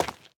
Minecraft Version Minecraft Version latest Latest Release | Latest Snapshot latest / assets / minecraft / sounds / block / dripstone / step2.ogg Compare With Compare With Latest Release | Latest Snapshot
step2.ogg